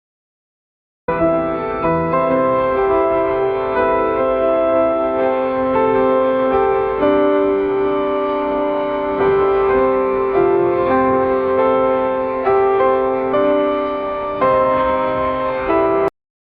音频生成示例
• 钢琴弹奏声（The sound of piano playing.）
钢琴弹奏的声音（The-sound-of-piano-playing.）-1.mp3